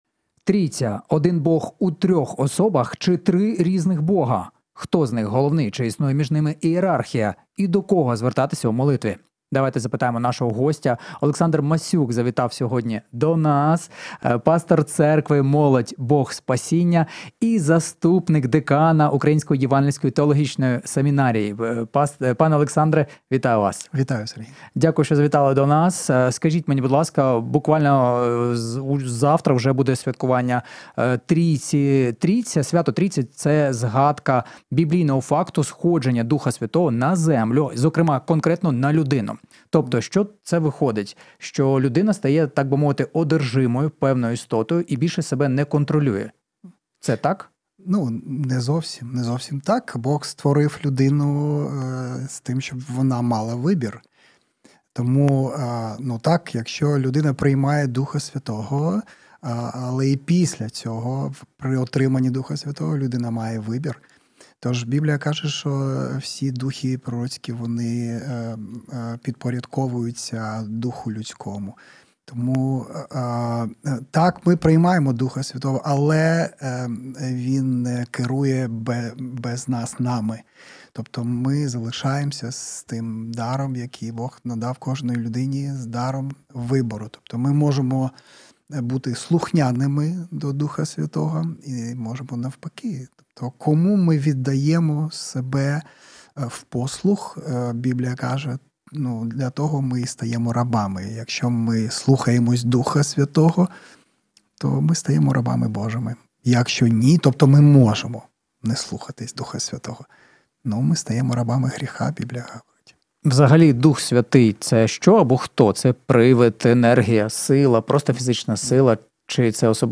Ефір програми Біблійний погляд Трійця — один чи три Бога?